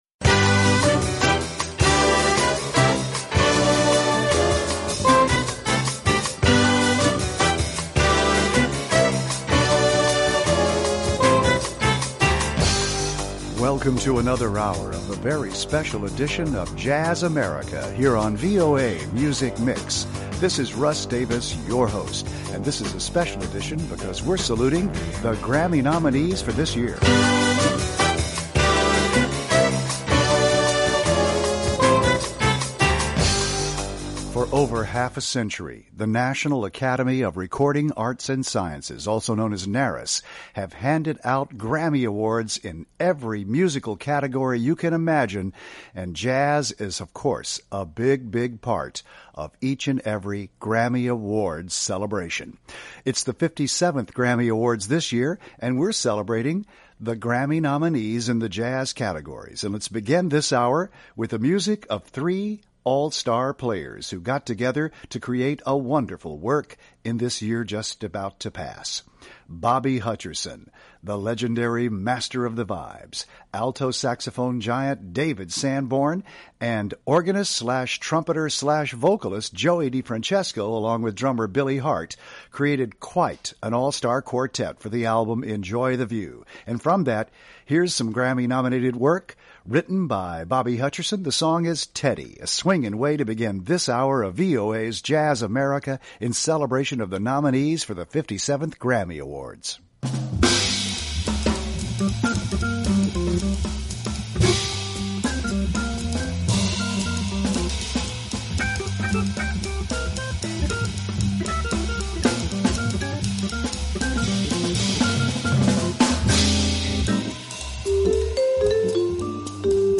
interviews a musician and features music from their latest recordings.